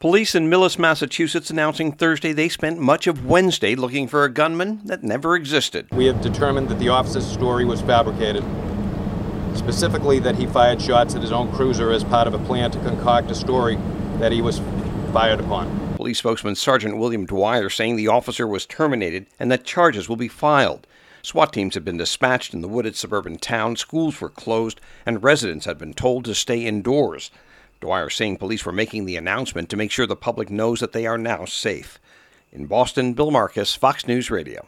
(Courtesy Fox 25) (BOSTON) SEP 3 – THE STORY OF SHOTS FIRED AT A POLICE CRUISER THAT CRASHED AND BURNED WEDNESDAY SETTING OFF A MASSIVE MANHUNT IN A SUBURBAN TOWN AN HOUR OUTSIDE OF BOSTON ALL MADE UP.